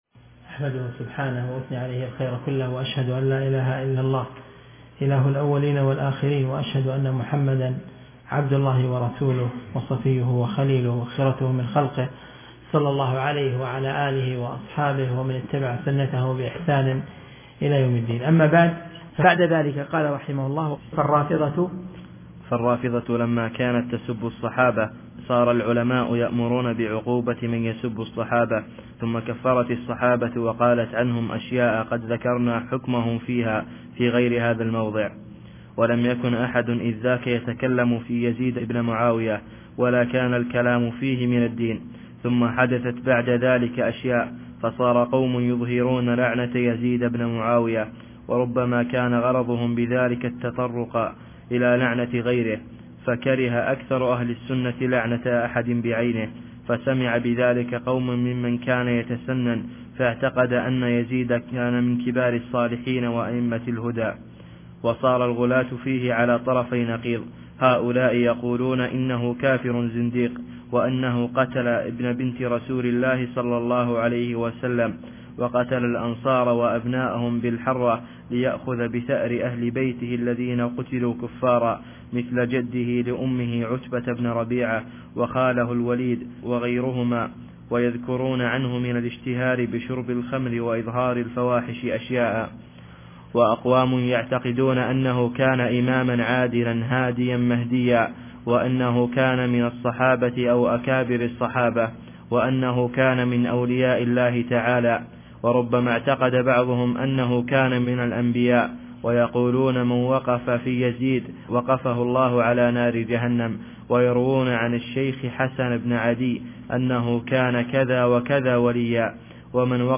الدرس (11) من شرح رسالة الوصية الكبرى